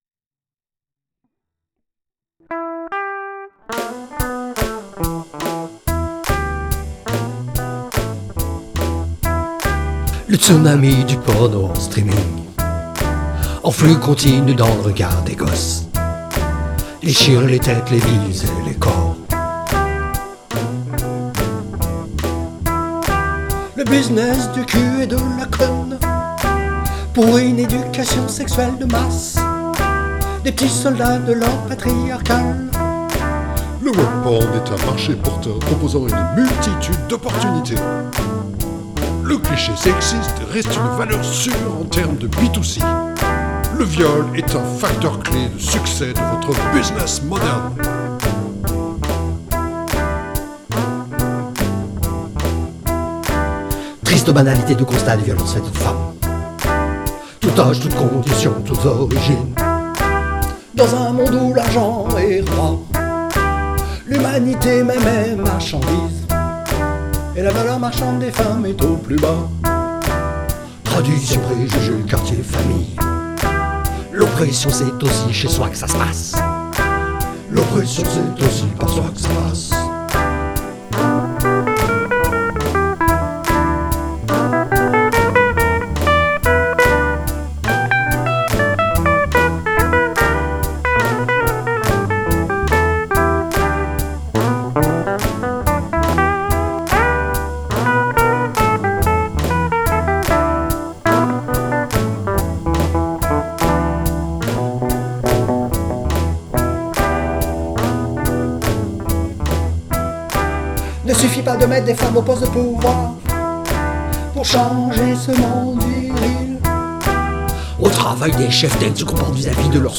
Crédit musical : riff inspiré de la chanson Smokestack Lightnin’ de Chester Arthur Burnett, alias Howlin’ Wolf, immense artiste du XXe siècle, à qui l’on doit tout.